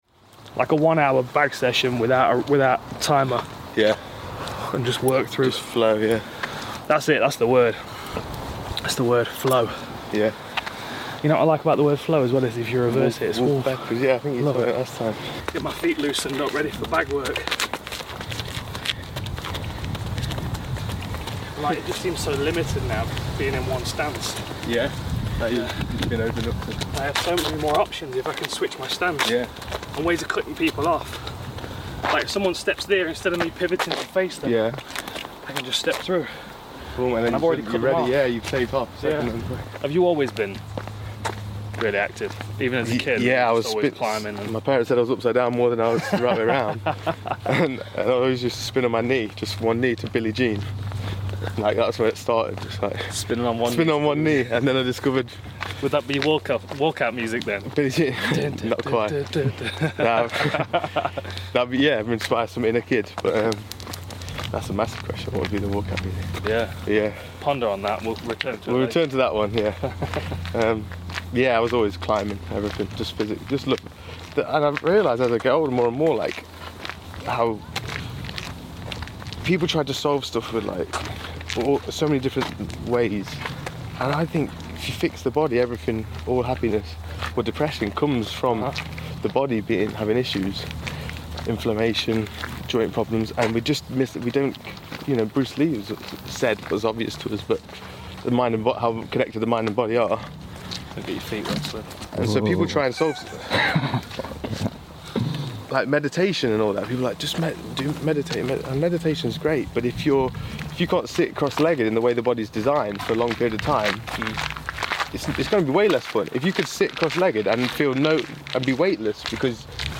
Dan hardy is joined by Tim Shieff for a Podcast & a stroll in nature to discuss training the body & mind, experiencing nature & Bruce Lee movies, recorded in and around Dan's house in July, 2019.